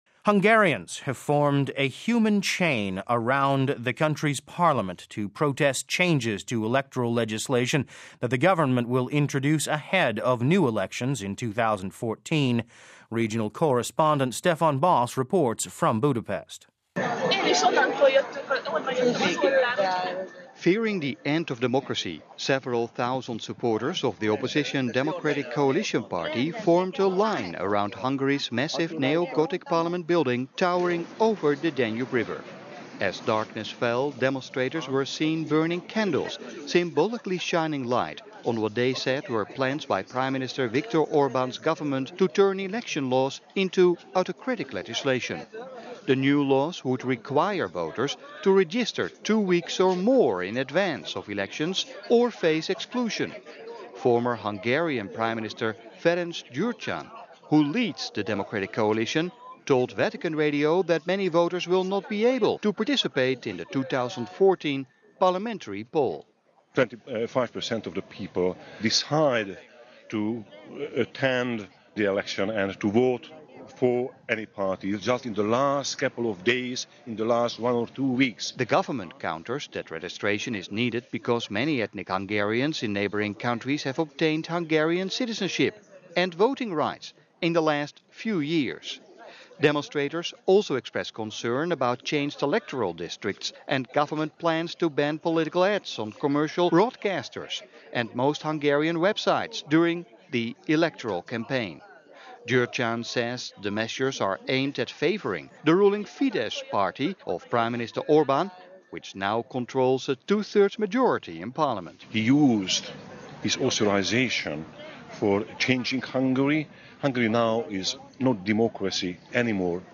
Former Hungarian Prime Minister Ferenc Gyurcsány, who leads the Democratic Coalition, told Vatican Radio that many voters will not be able to participate in the 2014 parliamentary poll.